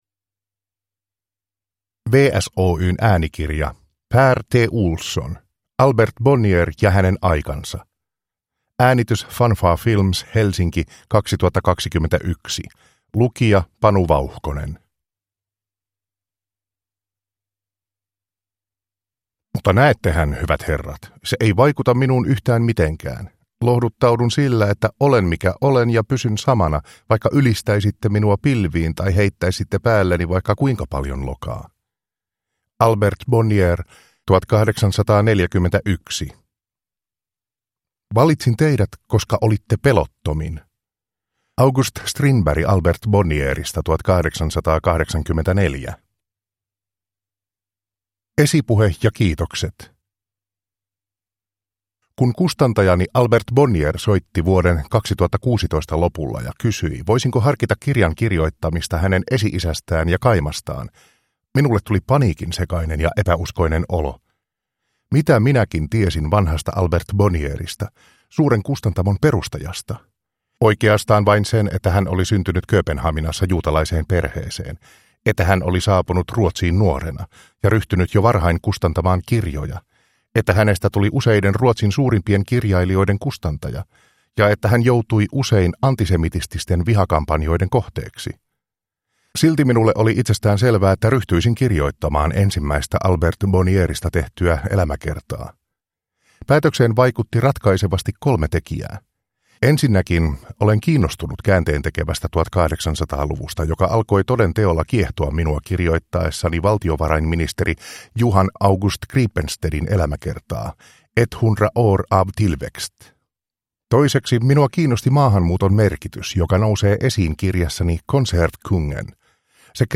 Albert Bonnier ja hänen aikansa – Ljudbok – Laddas ner